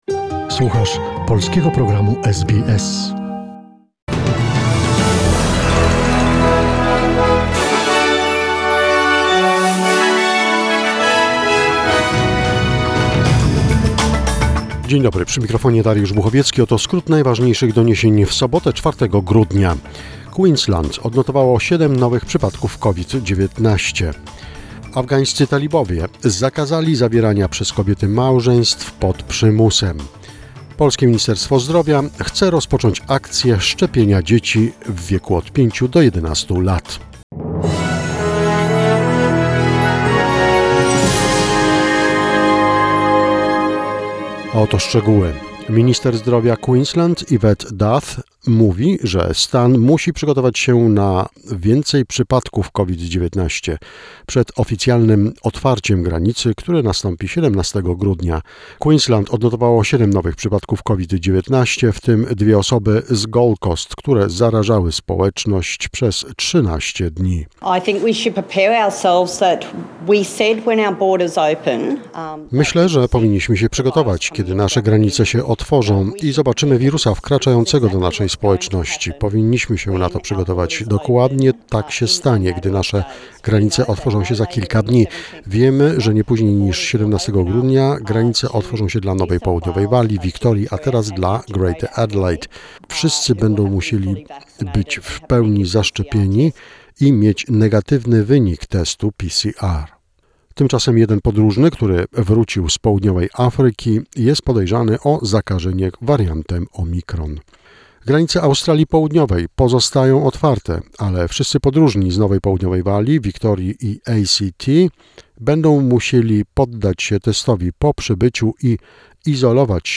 SBS News Flash in Polish, 4 December 2021